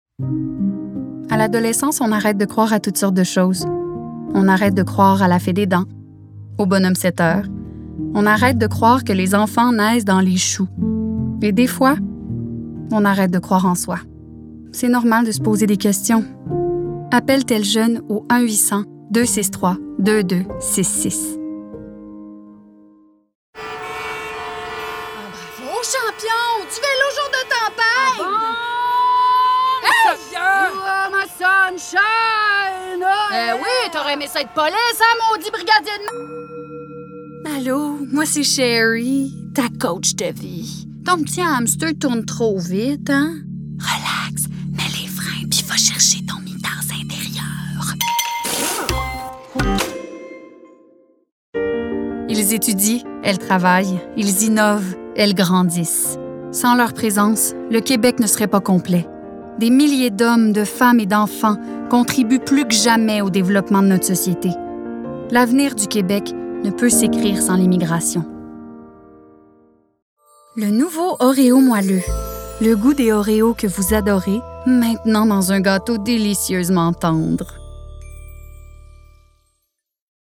Démo voix